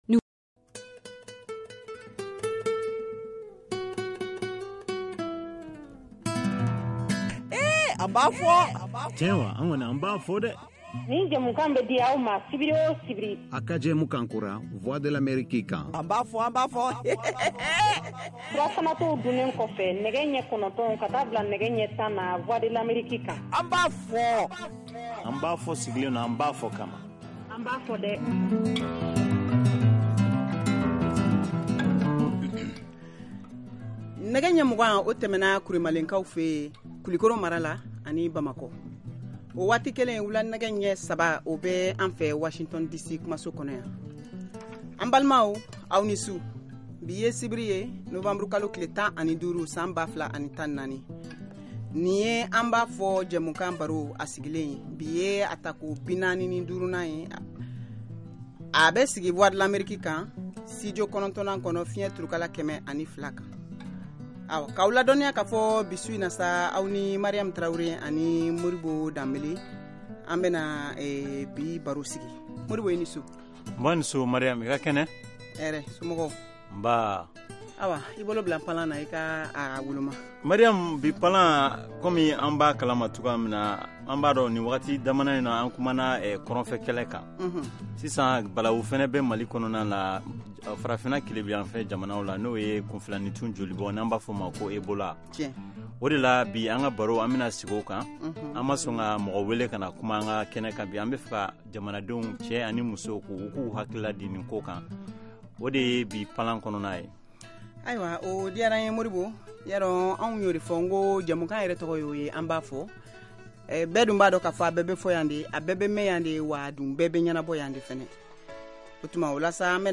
nouvelle émission interactive en Bambara diffusée en direct